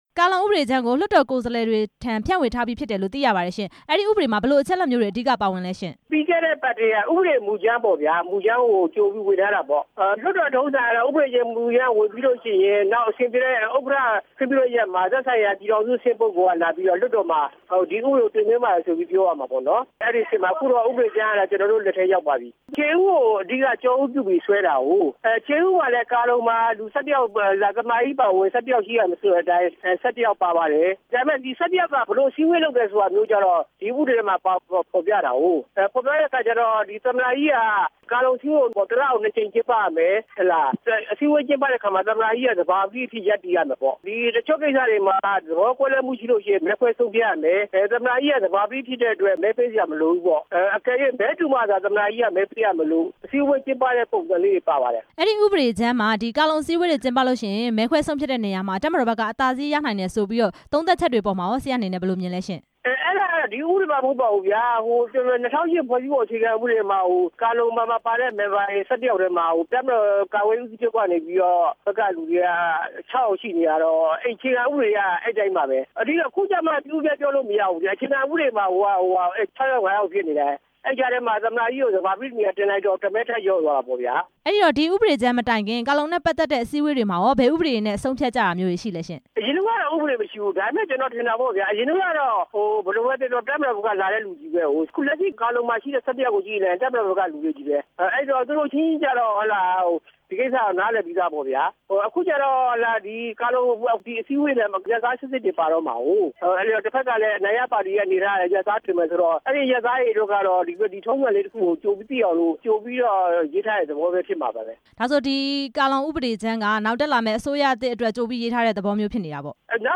(ကာလုံ) ဥပဒေကြမ်းနဲ့ ပတ်သက်ပြီး မေးမြန်းချက်